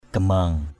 /ɡ͡ɣa-ma:ŋ/ (t.) trừng trừng = fixe. mata gamang mt% gm/ mắt trừng trừng = qui a le regard fixe.
gamang.mp3